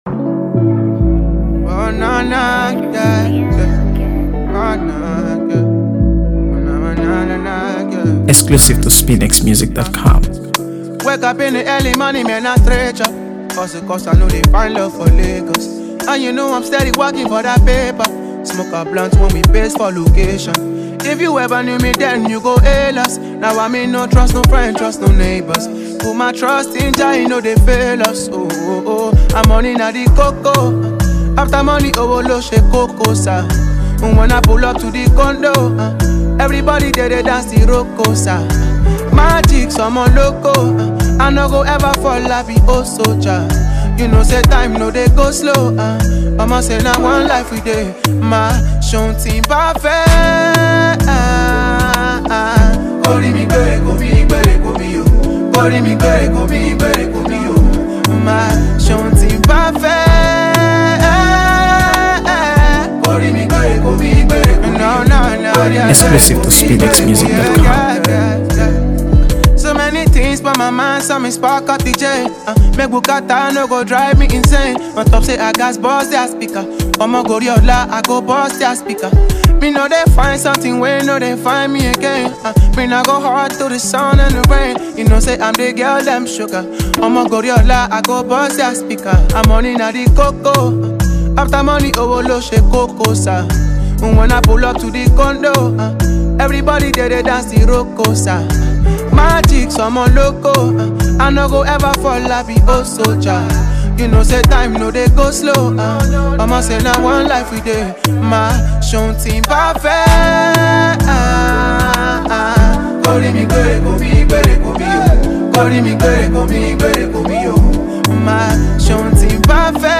AfroBeats | AfroBeats songs
signature smooth vocals